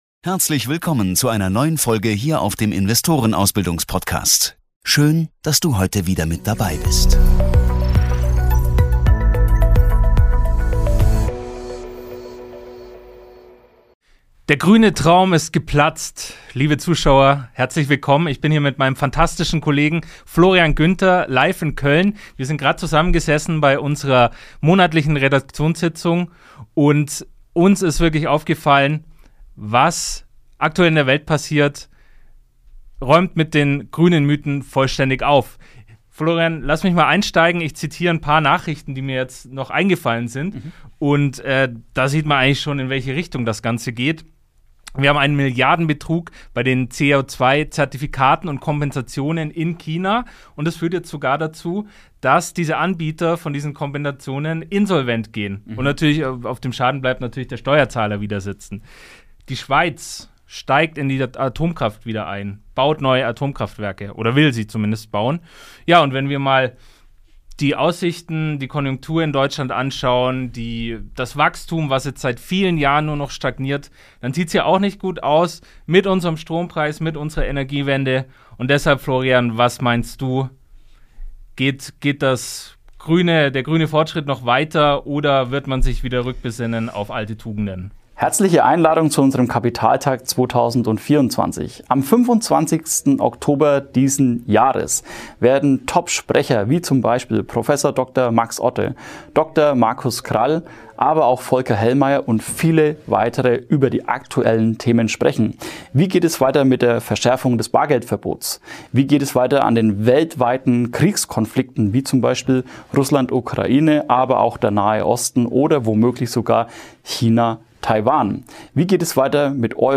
in unserem Studio Köln.